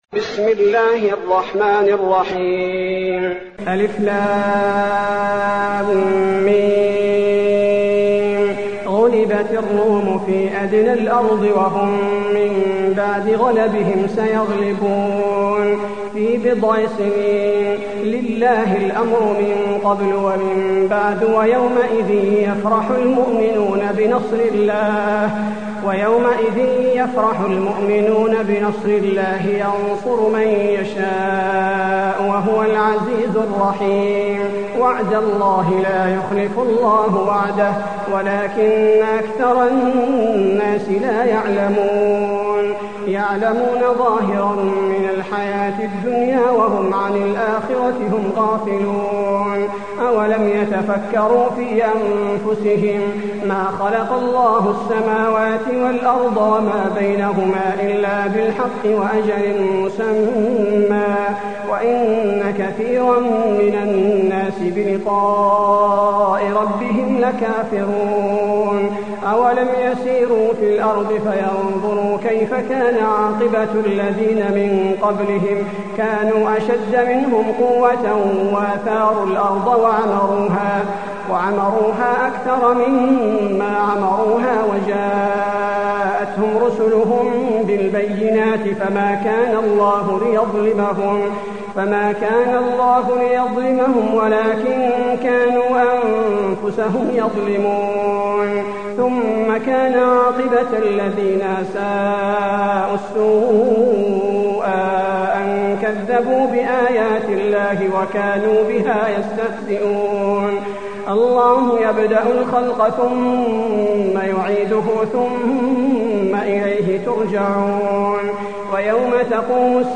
المكان: المسجد النبوي الروم